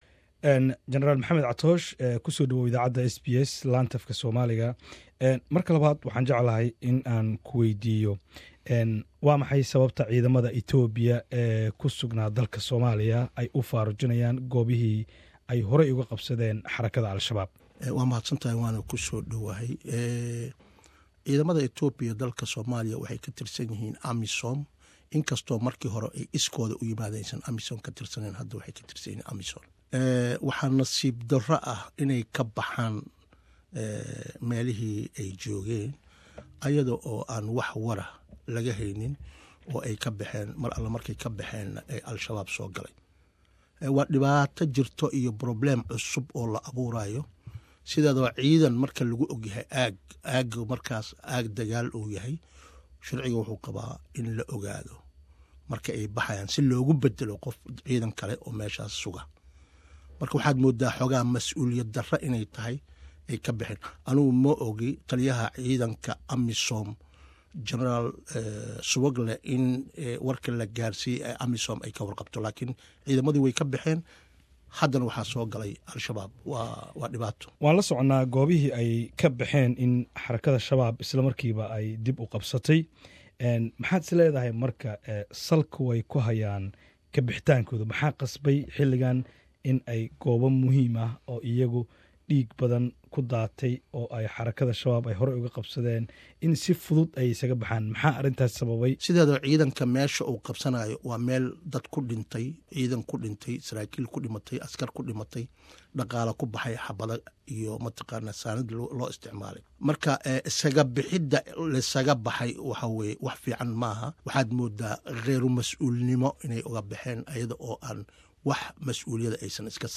Jen. Catoosh oo u Warramayay SBS ayaa sheegay in Ciidamada Itoobiya ee haatan Deegaannada Soomaaliya qaarkood ka baxaya ay iyagu markii hore si iskood ah dalka u soo galeen, haddana ay si iskood ah uga baxayaan.